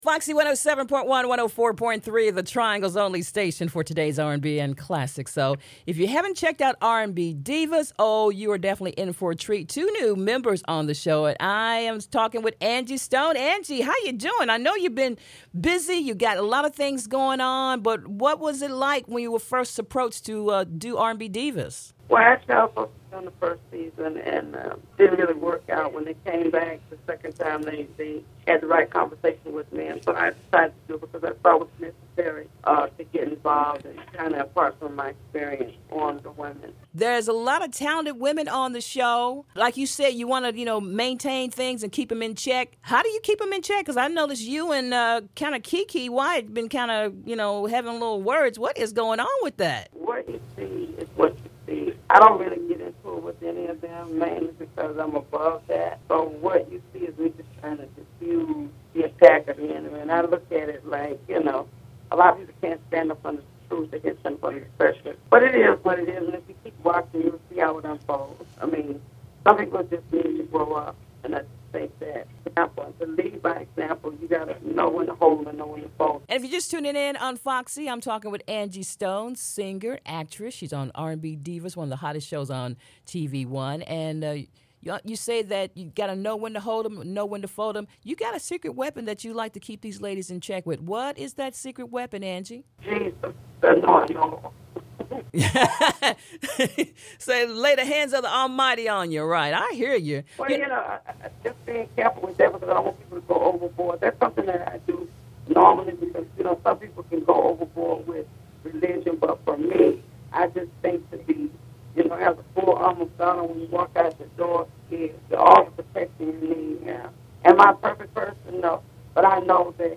angie-stone-interview-2013.mp3